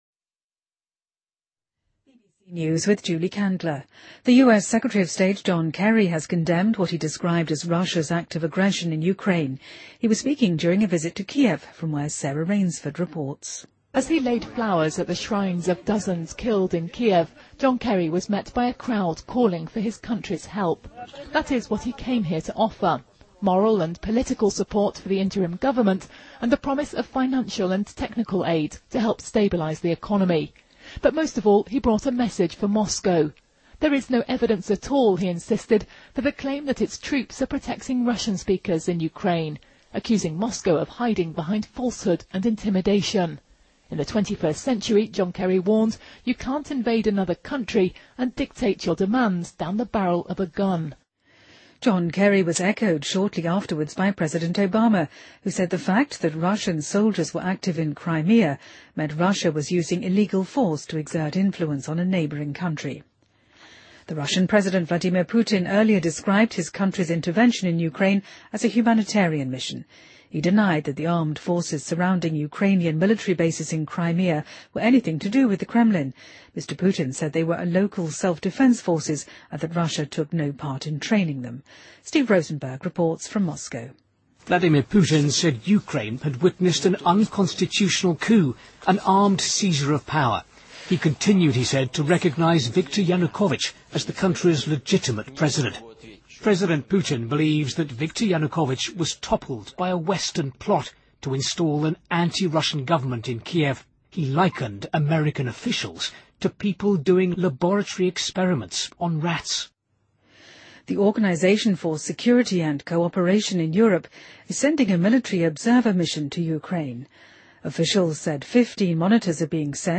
BBC news,2014-03-05